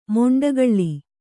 ♪ moṇḍagaḷḷi